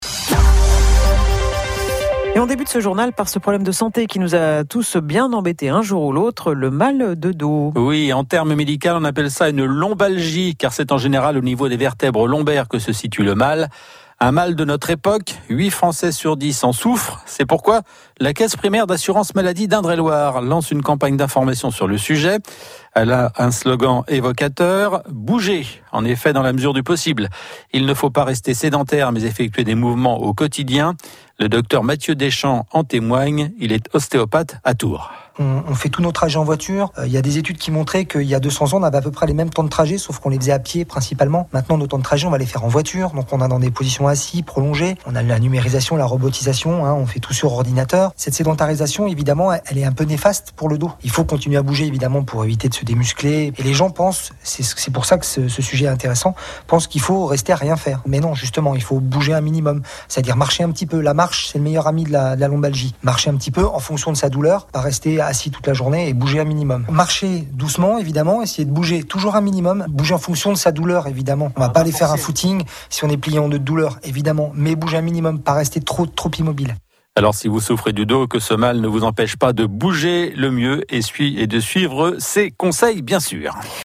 Dans le cadre de la campagne national pour la prévention de la lombalgie menée par l’assurance maladie, je suis intervenu le 19 décembre 2017 dans l’info matinale de France Bleu Touraine.